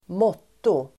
Ladda ner uttalet
Uttal: [²m'åt:o]